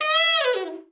ALT FALL   4.wav